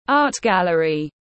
Triển lãm nghệ thuật tiếng anh gọi là art gallery, phiên âm tiếng anh đọc là /ˈɑːt ˌɡæl.ər.i/.